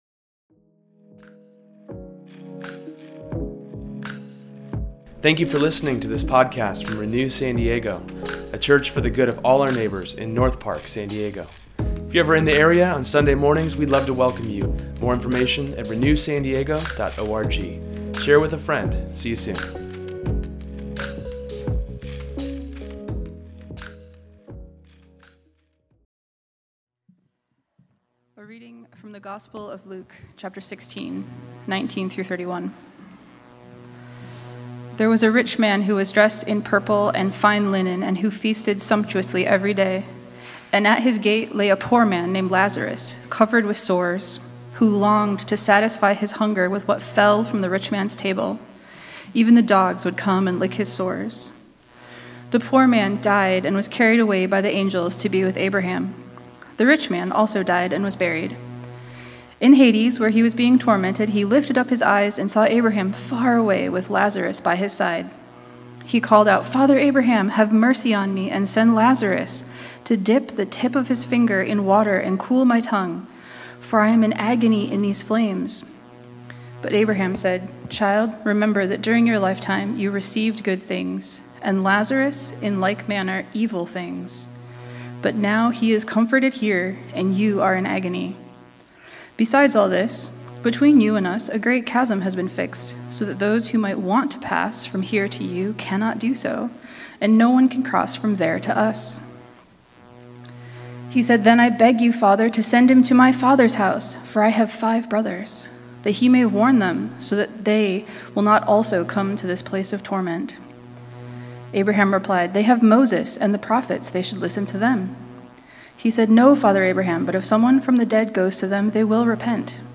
Today, the sermon looks at the parable of the rich man and Lazarus, to reflect on why how you live now is important.